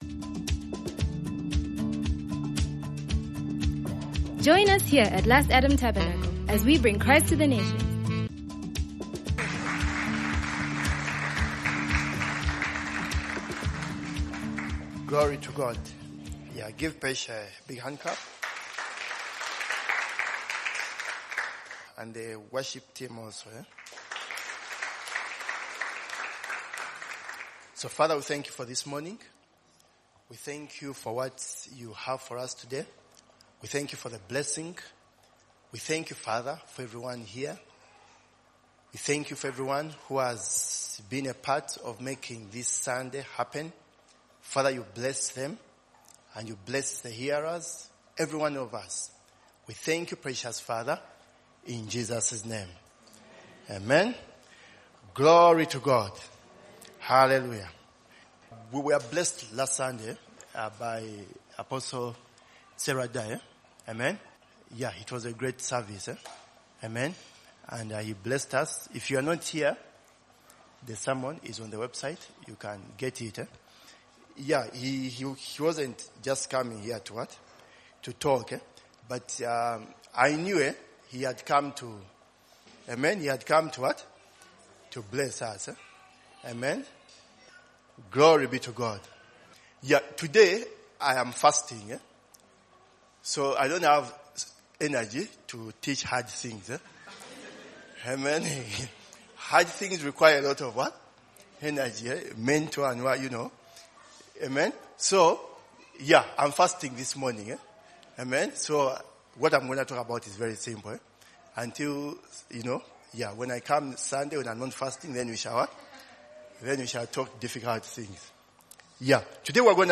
Honoring Those Who Have Gone Before A sermon